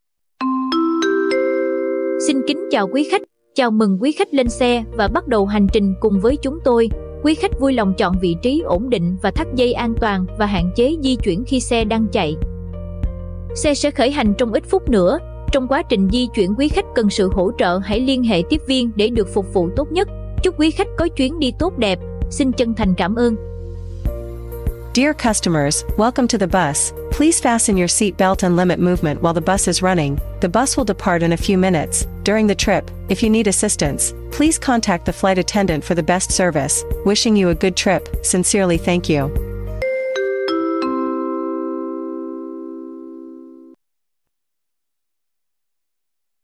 Âm thanh Chào mừng Lên xe (cho Xe Khách)
Thể loại: Tiếng chuông, còi
Nội dung giọng nói nữ nhân viên nhẹ nhàng, thân thiện, tạo cảm giác thoải mái: Xin kính chào quý khách!
am-thanh-chao-mung-len-xe-cho-xe-khach-www_tiengdong_com.mp3